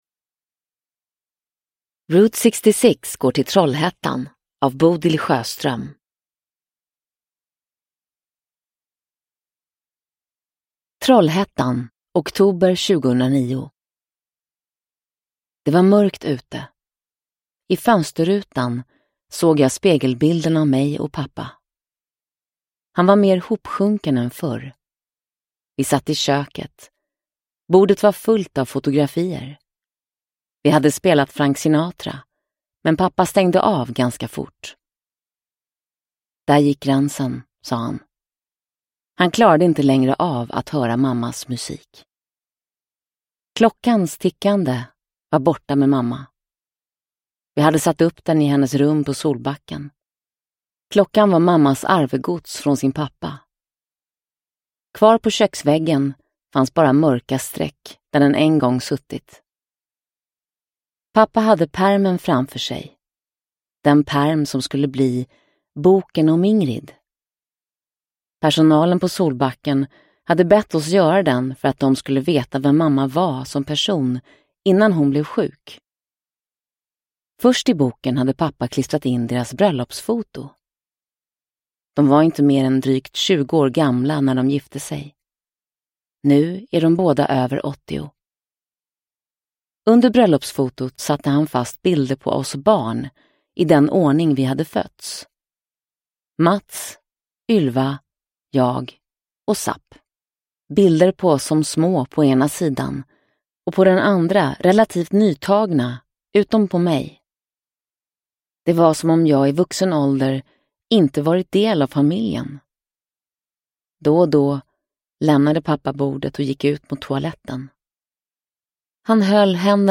Route 66 går till Trollhättan – Ljudbok – Laddas ner